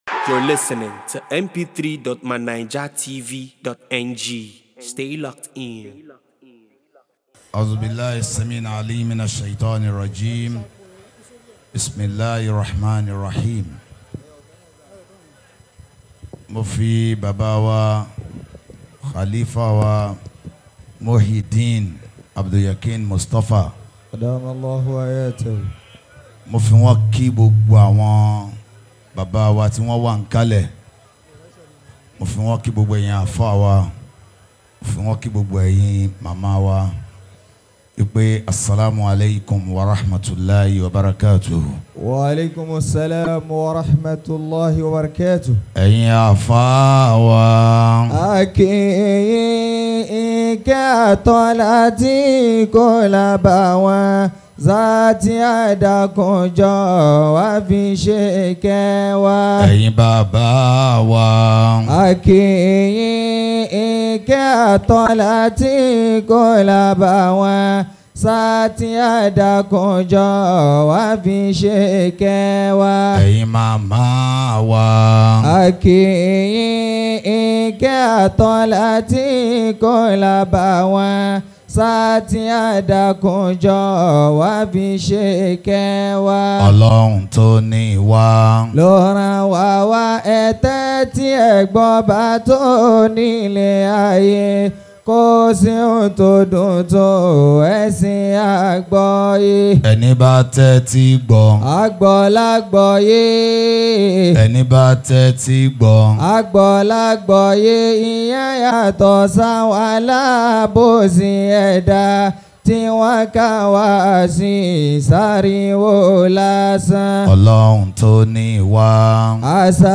at the 41st Mawlid Nabbiyy Celebration of Al-Isobatul Qadiriyah Al-Islamiyyah Worldwide on the 20th of September 2025